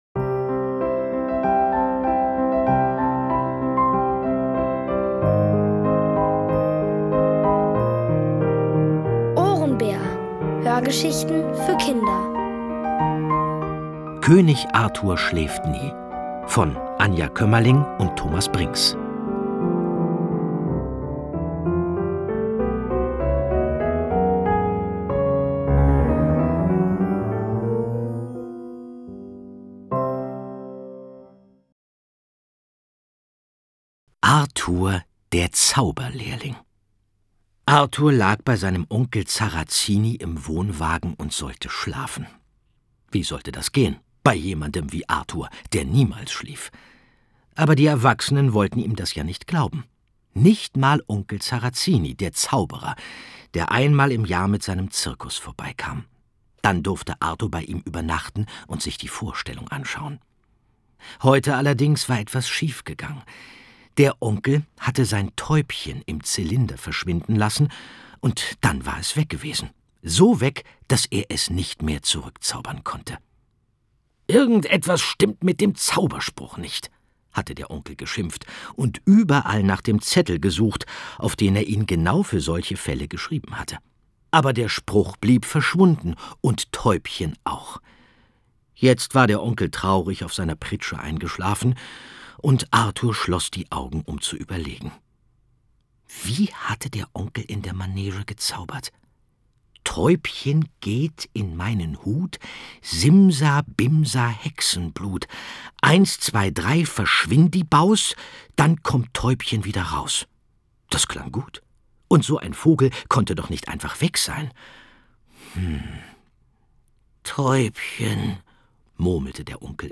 Von Autoren extra für die Reihe geschrieben und von bekannten Schauspielern gelesen.
Es liest: Andreas Fröhlich.